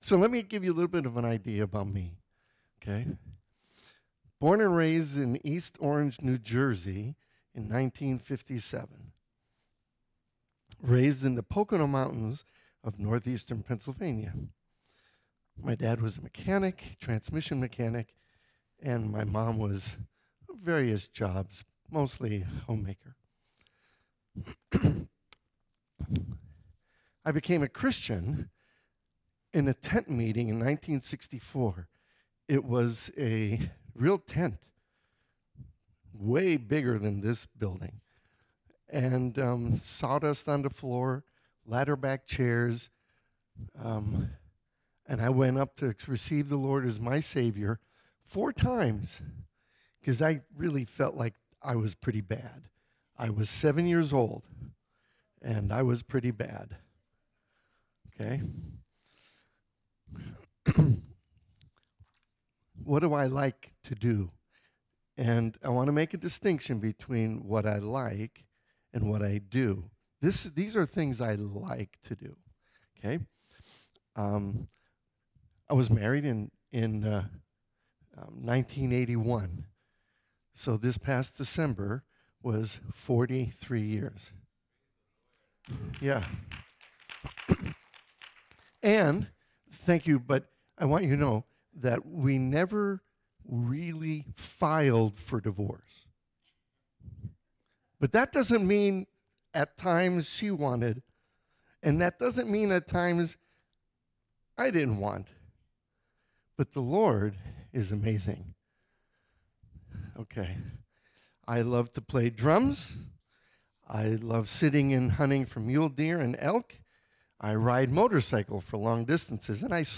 2025 Our True Selves Preacher